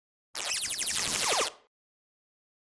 Beeps Sci-Fi Space Machine Beep 5